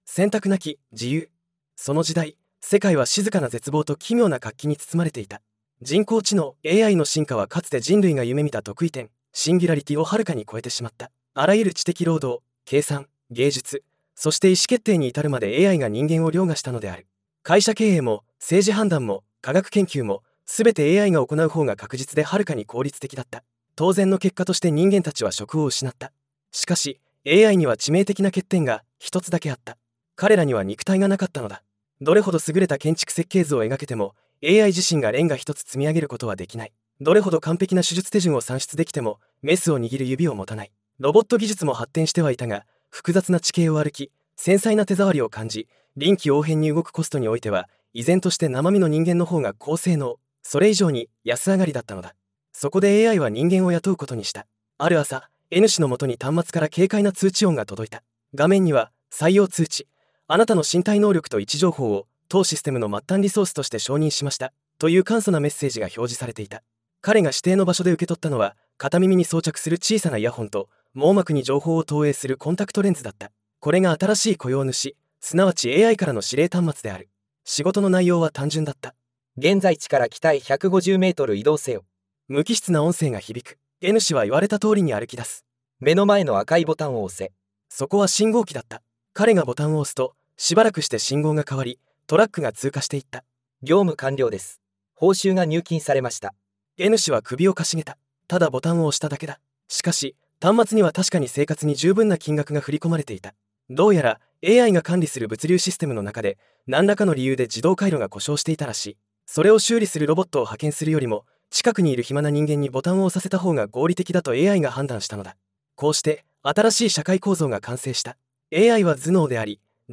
※ショートショートの音声読み上げはブログ記事と分けています。